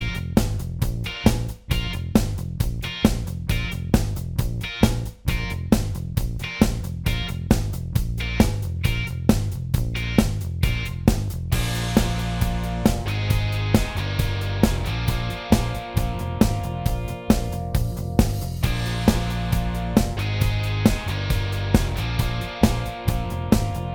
Minus Lead And Solo Guitars Rock 4:48 Buy £1.50